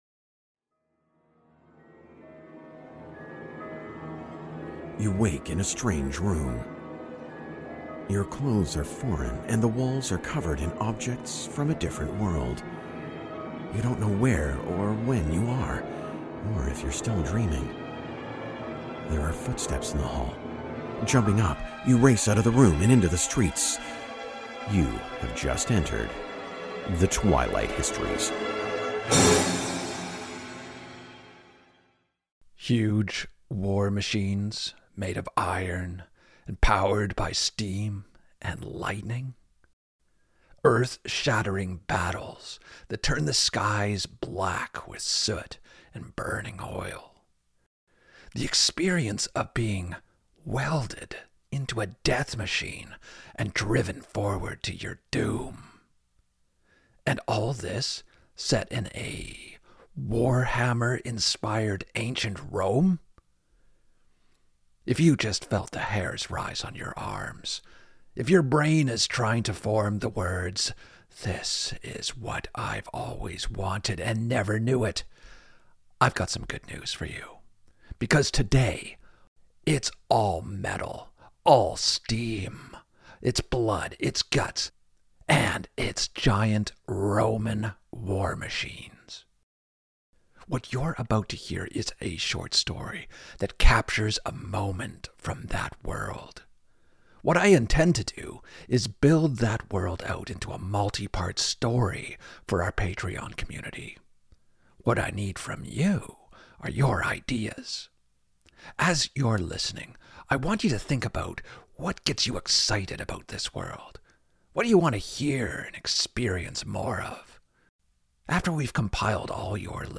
This is an alternate history story that casts YOU as the hero, in an RPG style adventure tale using music and sound effects.